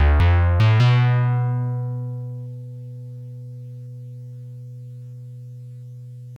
talking.ogg